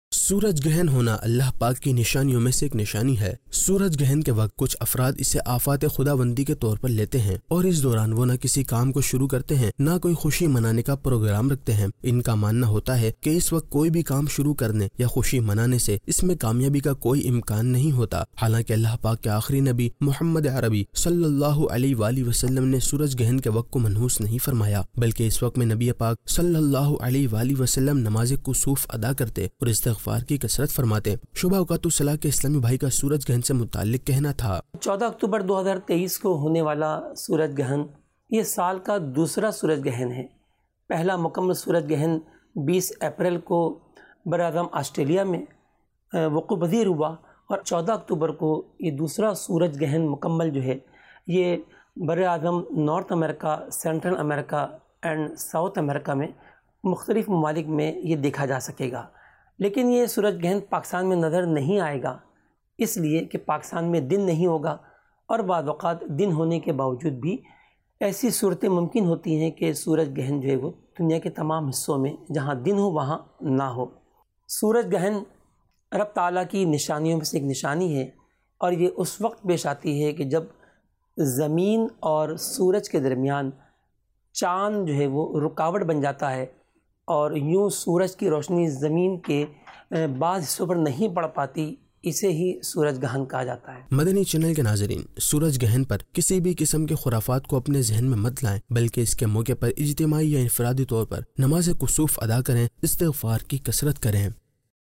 News Clips Urdu - 13 October 2023 -Sooraj Girhan Allah Pak Ki Nishaniyon Mein Se Aik Nishani Hai Oct 31, 2023 MP3 MP4 MP3 Share نیوز کلپس اردو - 13 اکتوبر 2023 -سورج گرہن اللہ پاک کی نشانیوں میں سے ایک نشانی ہے